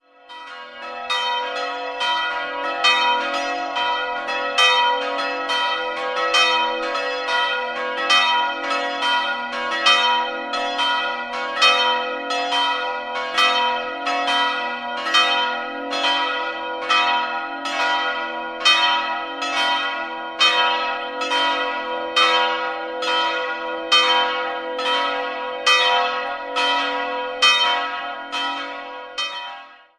Die Kirche entstand 1698 zusammen mit dem Schlossbau. 3-stimmiges Geläute: h'-cis''-e'' Die Glocken wurden im Jahr 1946 von Karl Hamm in Regensburg gegossen.